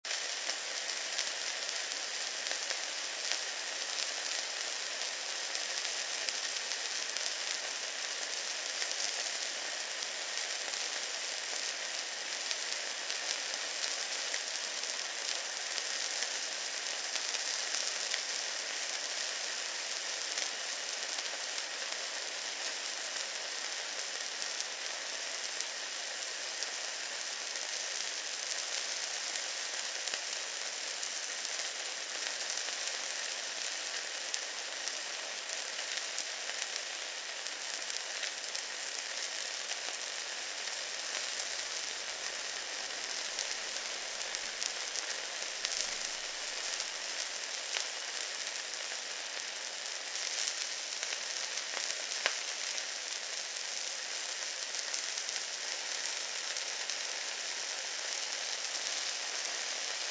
Sizzle
Note that the samples are mp3 (lower quality than the CD) to keep download times short.
The steak is on the grill, and there is no other sound. Just the sizzle and pop as clouds of steam, a little smoke, and layers of that fabulous aroma curl lazily through the air around you.
off-white-noise-sizzle-60s.mp3